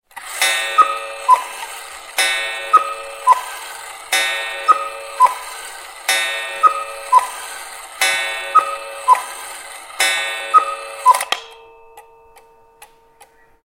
Catégorie: Alarmes